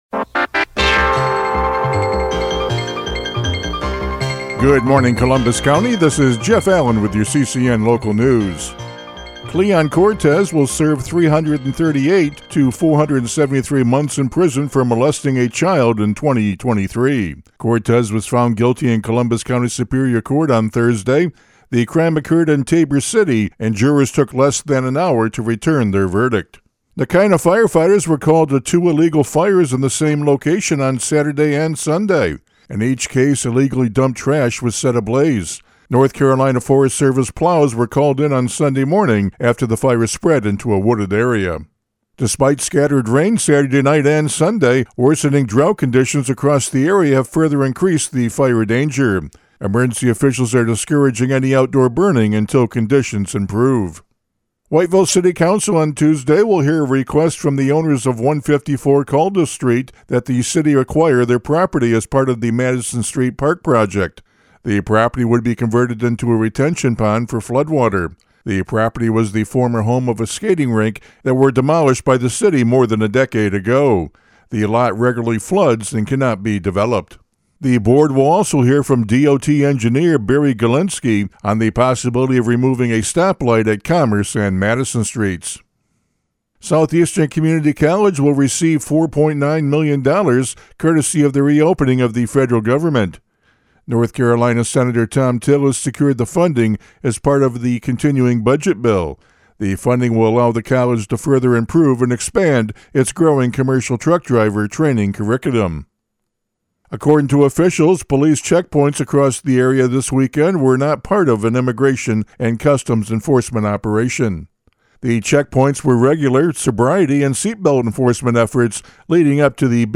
CCN Radio News — Morning Report for November 24, 2025